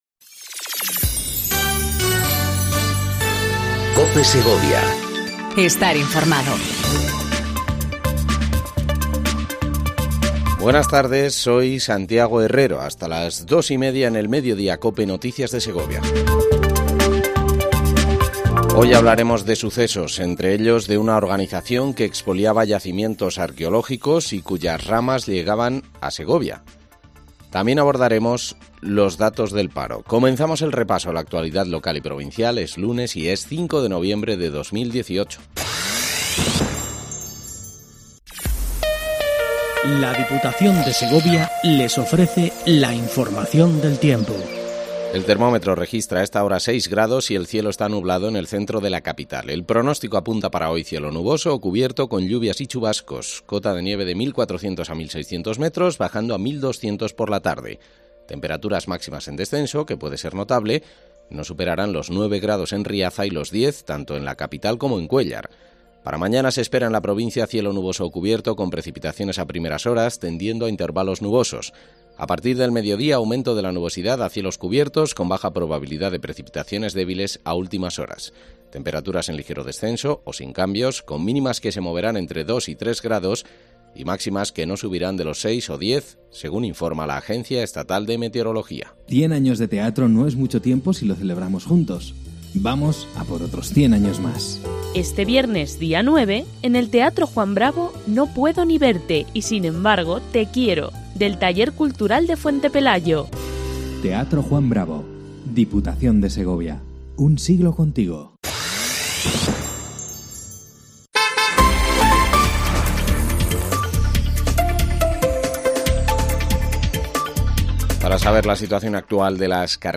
INFORMATIVO MEDIODÍA COPE SEGOVIA 14:20 DEL 05/11/18